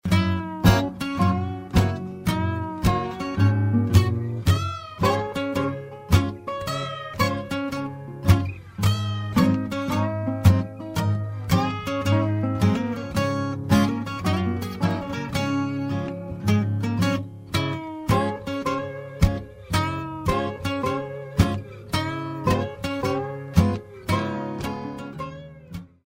All FAT HEAD SAMPLES ARE RECORDED WITH A  STOCK TRANSFORMER
Acoustic Guitar: